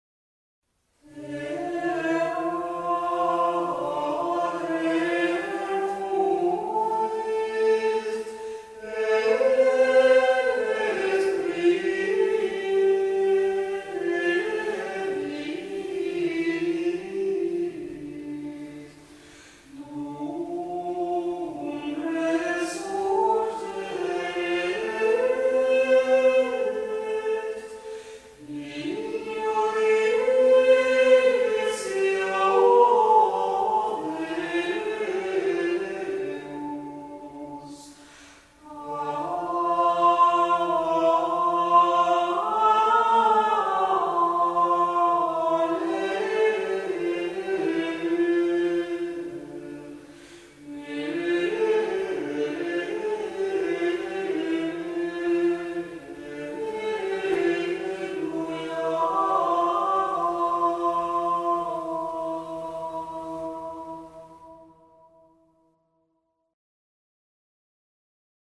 Mosteiro de San Lourenzo de Carboeiro
Antifona_Terra_Tremuit_mIV.mp3